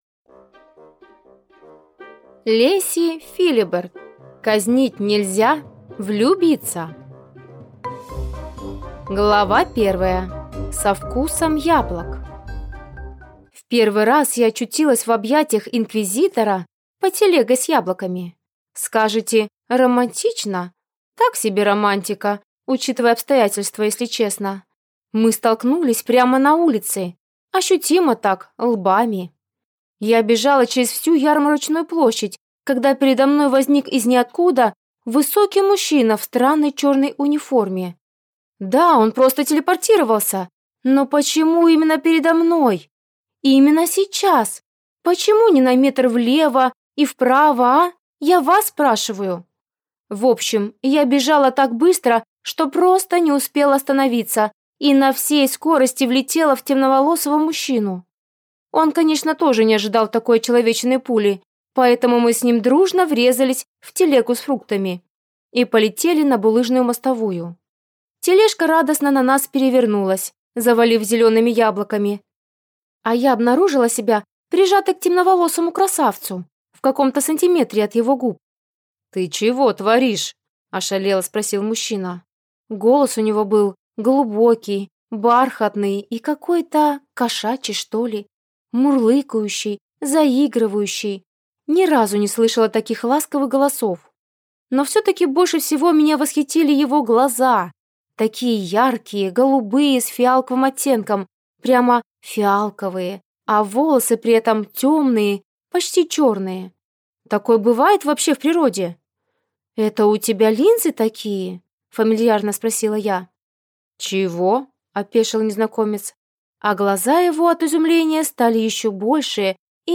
Аудиокнига Казнить нельзя влюбиться | Библиотека аудиокниг